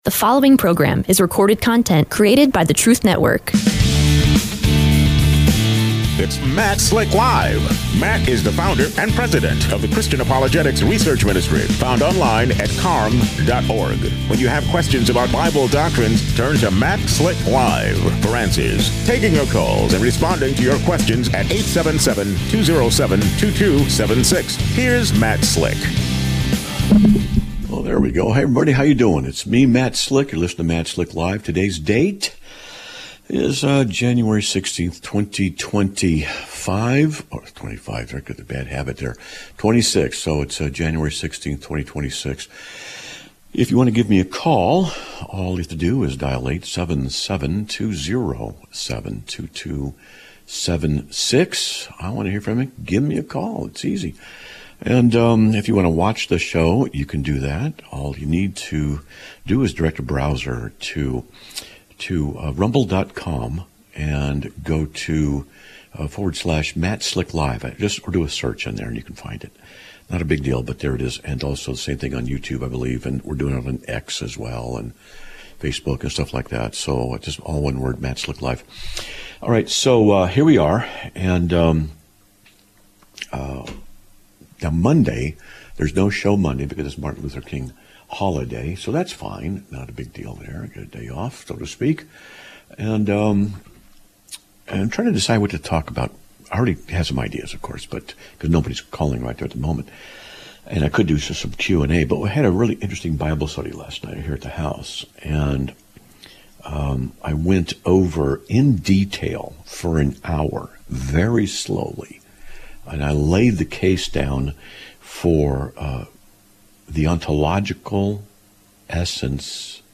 Live Broadcast of 01/16/2026